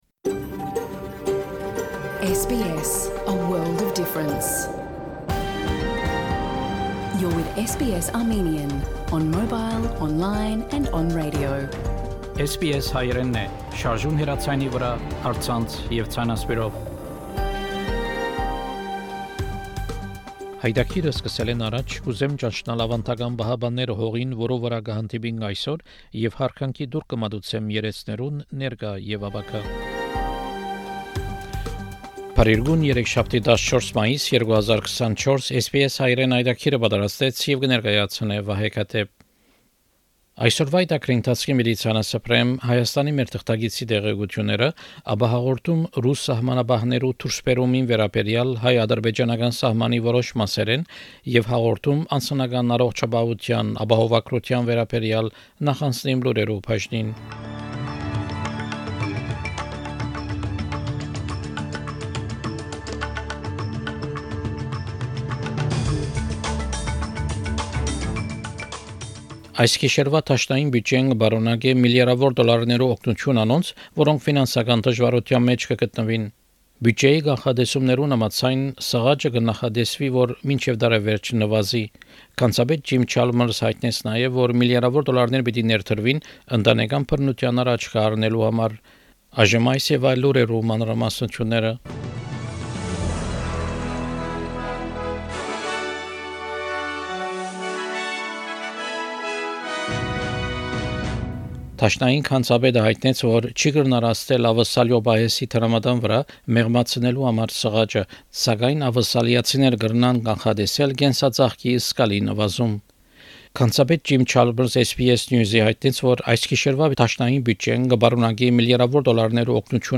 SBS Armenian news bulletin – 14 May 2024
SBS Armenian news bulletin from 14 May program.